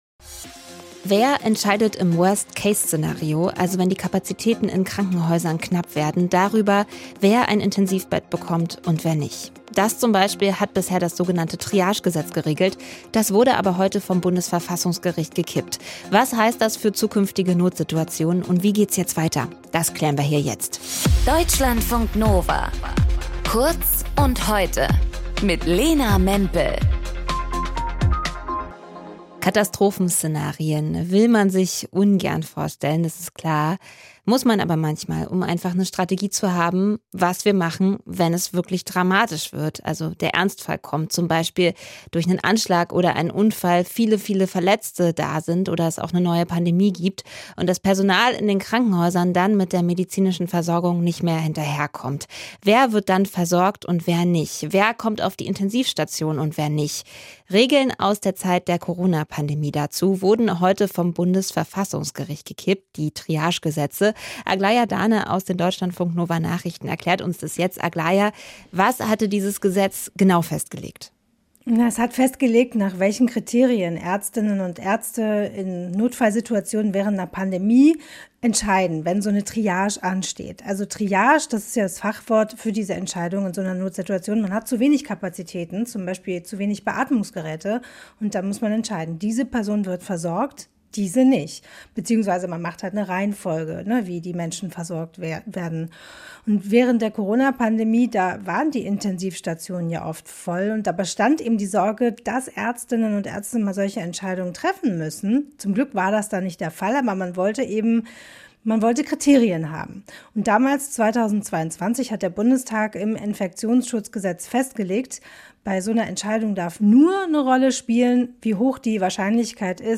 Kommentar zur Triage - Den Verfassungsrichtern fehlte der Mut